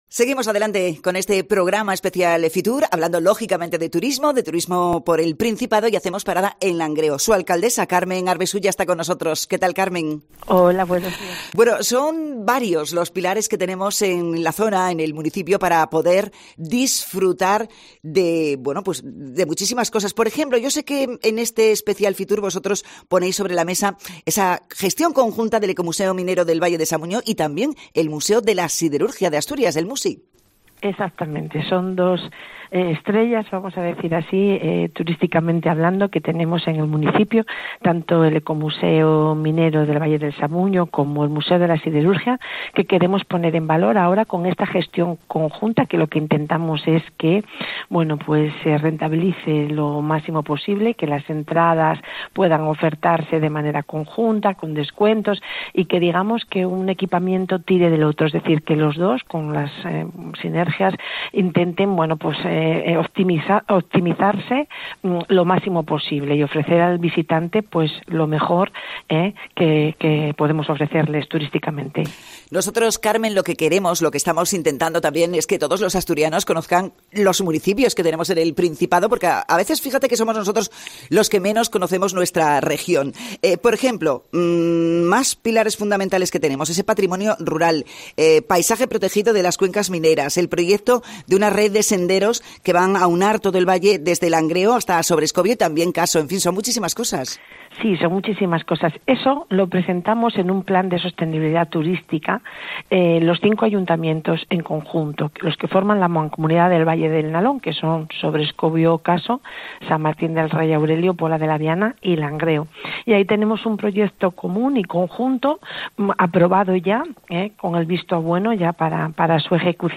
Entrevista con la alcaldesa de Langreo, Carmen Arbesú